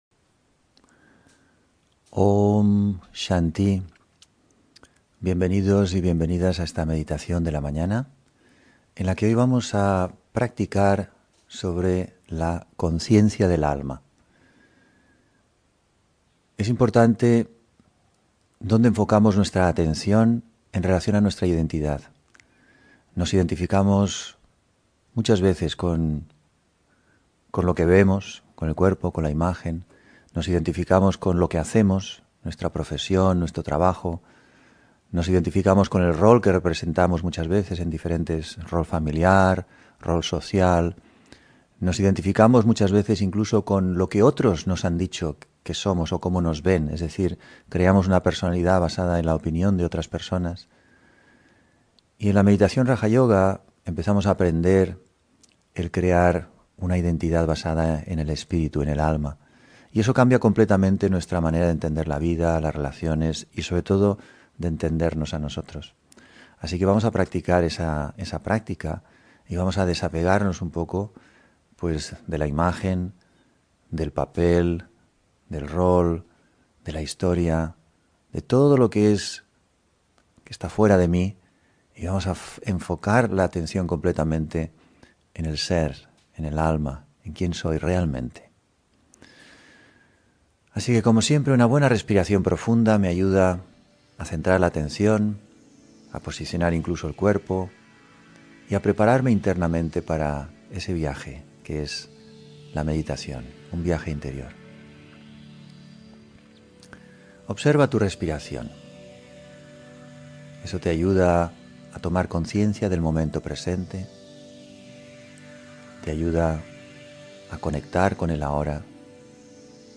Meditación de la mañana: Yo soy un alma